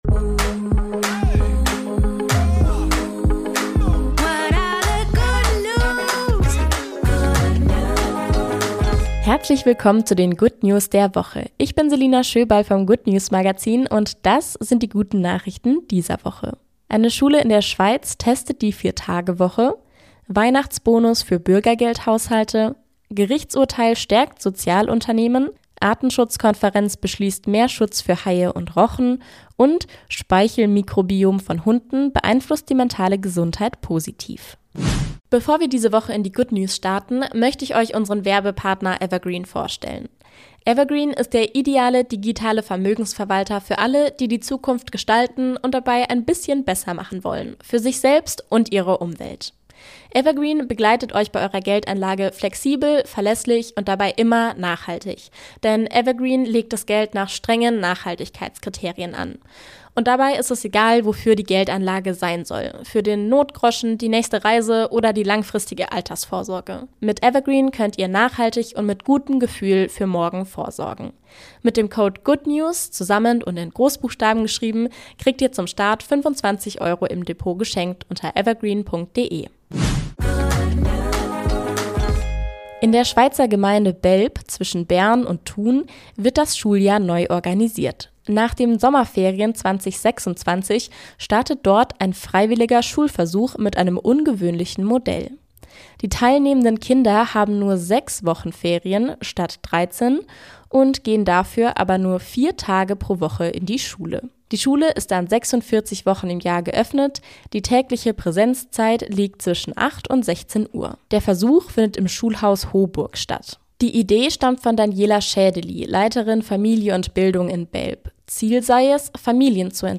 Jede Woche wählen wir aktuelle gute Nachrichten aus und tragen sie